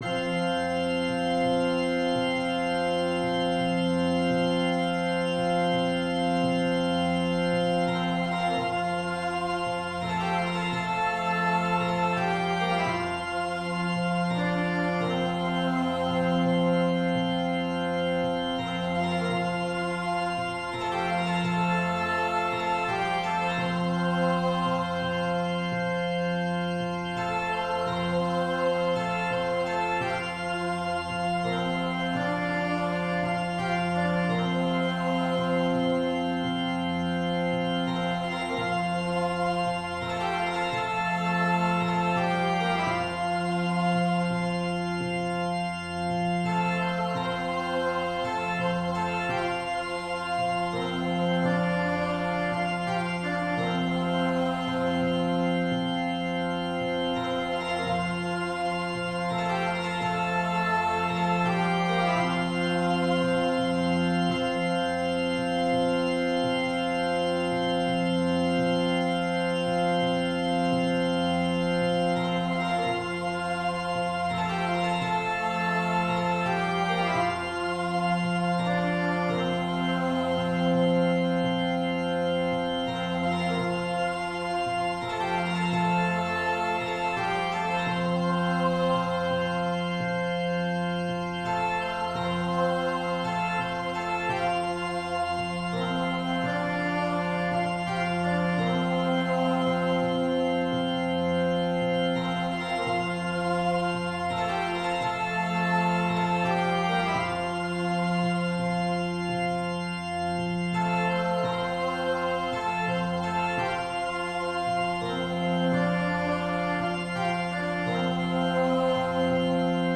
Voicing/Instrumentation: Choir Unison , Organ/Organ Accompaniment , Percussion We also have other 49 arrangements of " Amazing Grace ".
Simplified Arrangement/Easy Play
I LOVE these fake bagpipes!